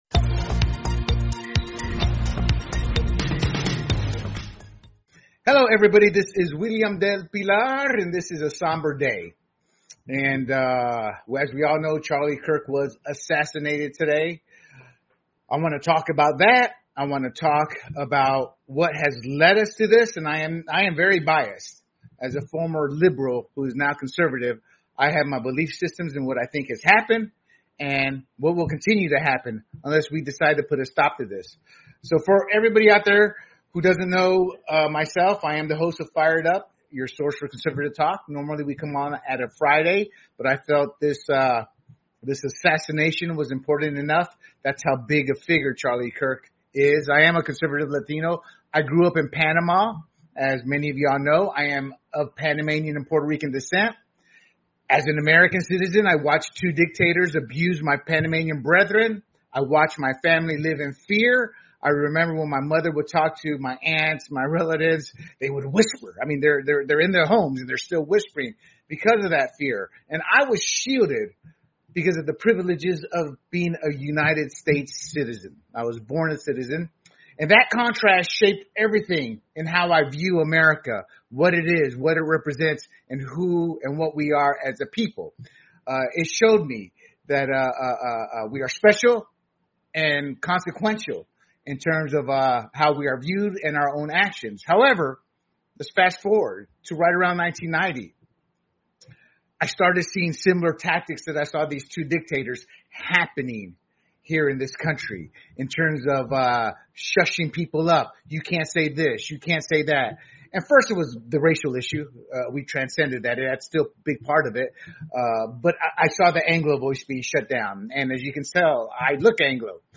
a panel of guests reflect on the life, influence, and enduring legacy of Charlie Kirk